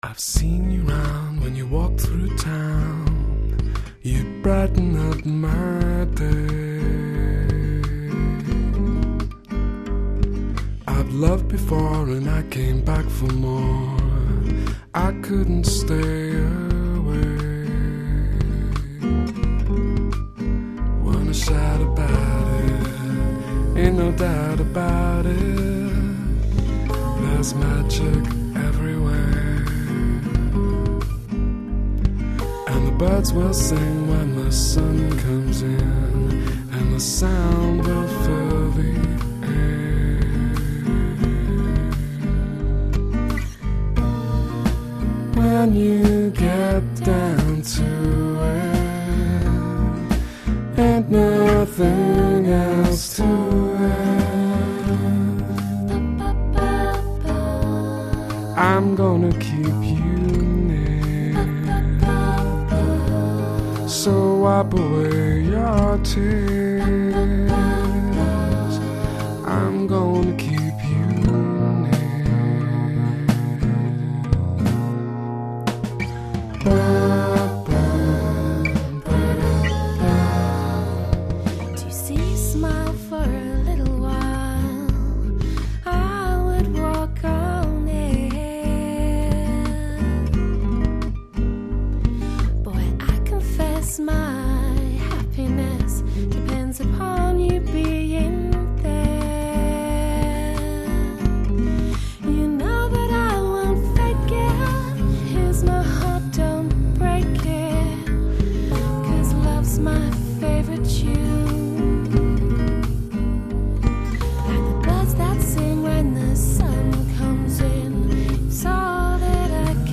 Folky guitars, jazzy melodies, sweet harmonies.